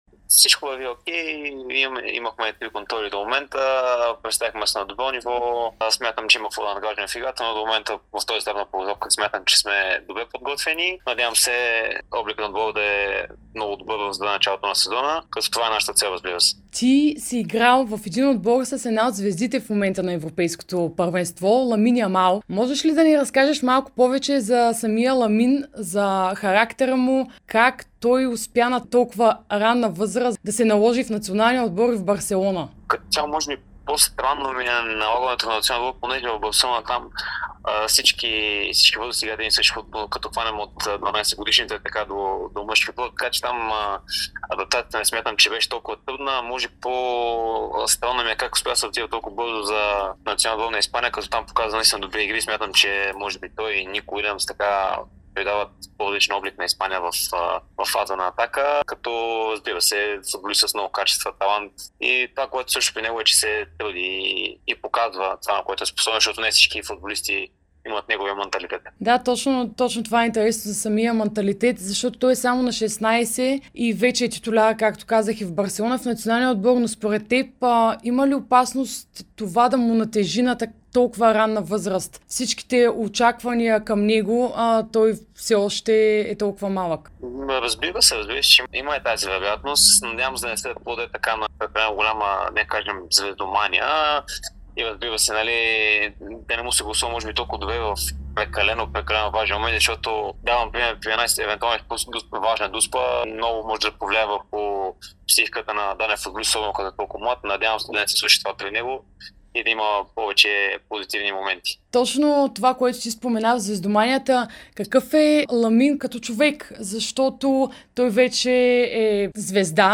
даде интервю пред Дарик и dsport преди 1/4-финалите на Европейското първенство.